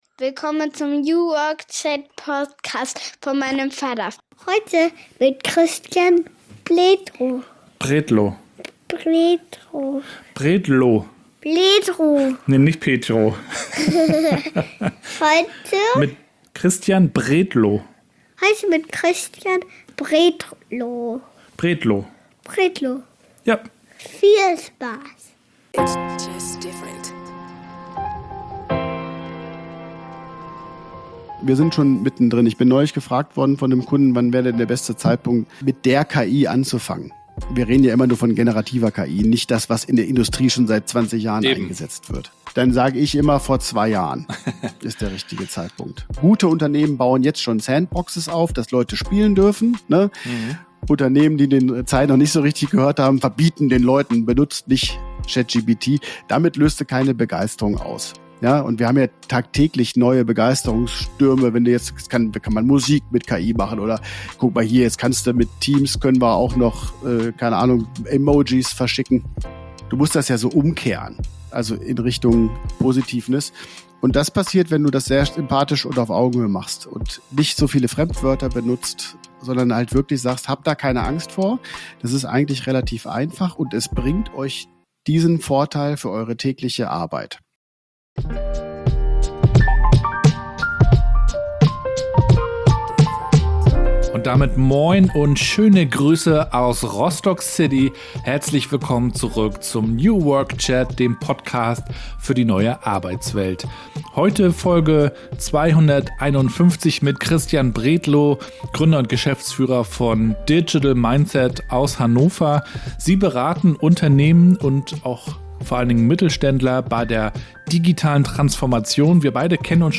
In meinem Podcast „New Work Chat“ interviewe ich spannende Köpfe rund um die Themen New Work, Kultur und Transformation.